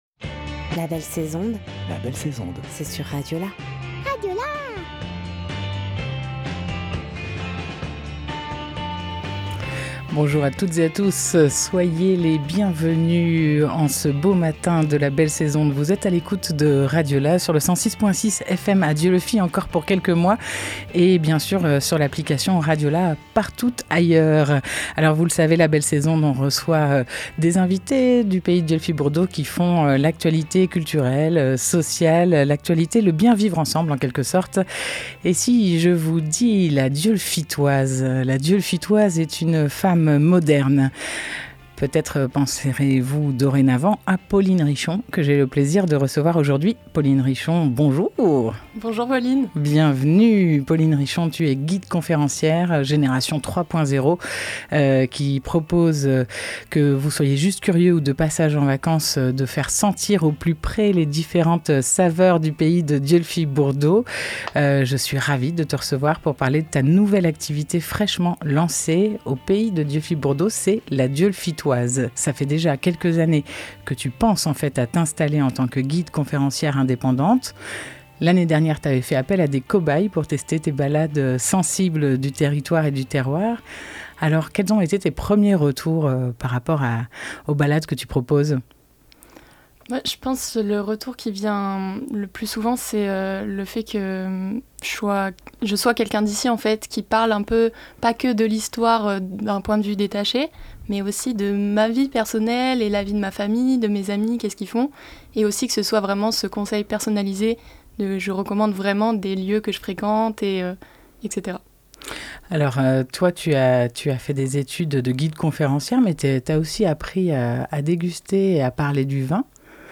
12 juillet 2023 11:16 | Interview, la belle sais'onde